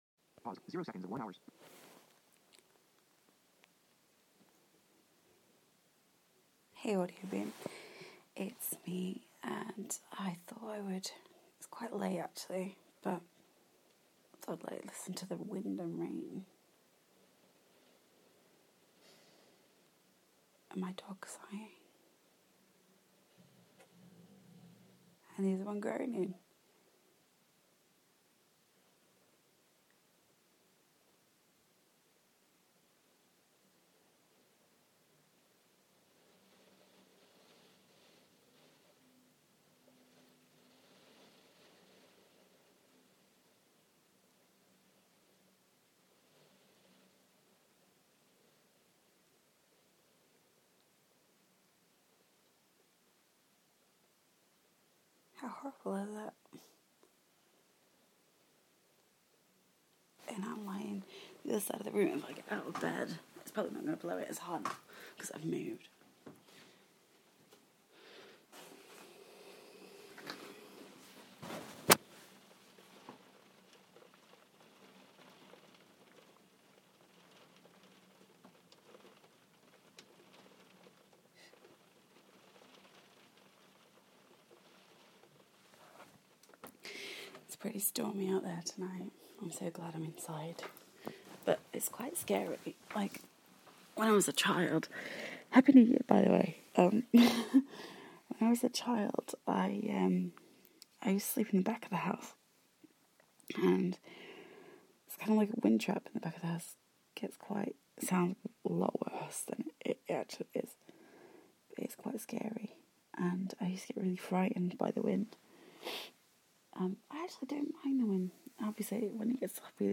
just a stormy night